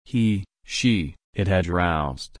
Past participle
/ˈɹaʊzd/